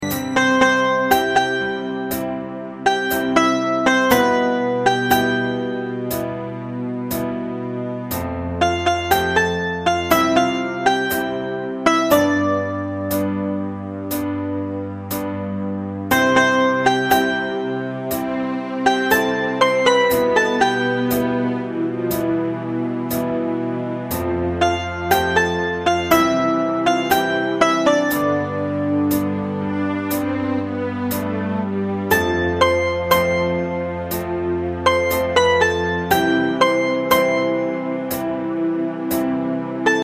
大正琴の「楽譜、練習用の音」データのセットをダウンロードで『すぐに』お届け！
カテゴリー: ユニゾン（一斉奏） .
ポピュラー